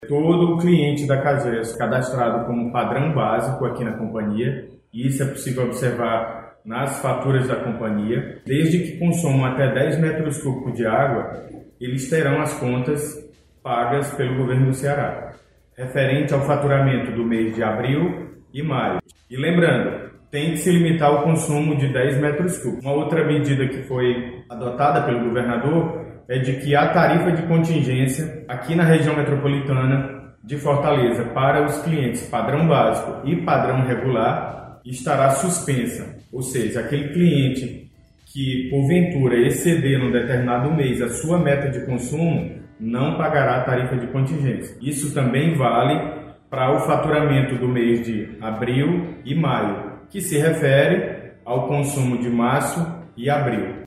O presidente da Cagece, Neuri Freitas, reforça que além da suspensão da tarifa de água e esgoto para esses segmentos, o Governo do Ceará também suspendeu a tarifa de contingência.